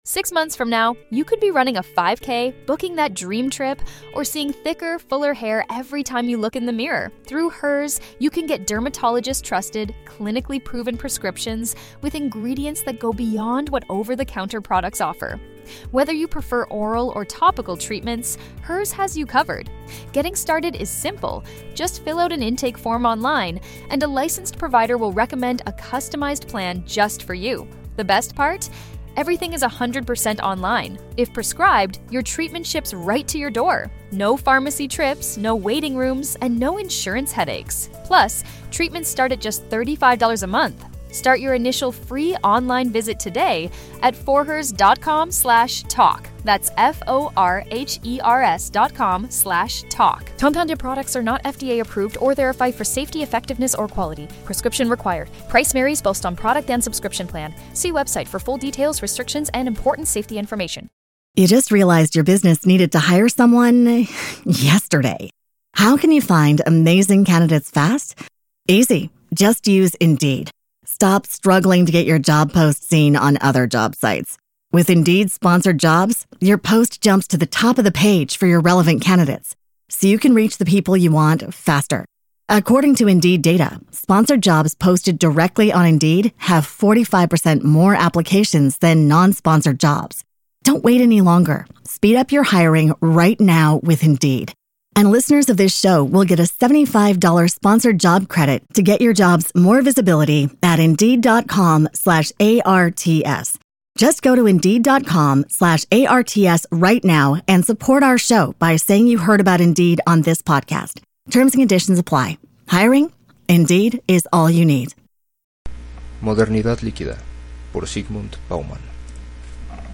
"Napoleón en Chamartin" de Benito Pérez Galdós 26-27 (Episodios Nacionales V) – La Audioteca, libros para escuchar – Podcast